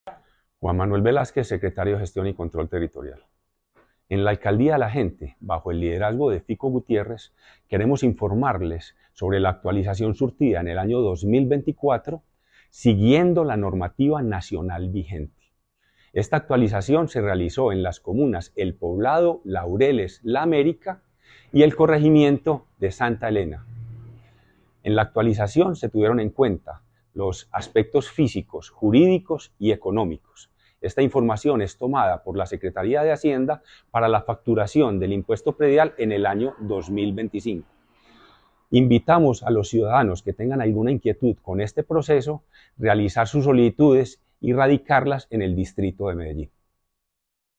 Palabras de Juan Manuel Velásquez, secretario de Gestión y Control Territorial